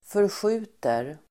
Uttal: [försj'u:ter]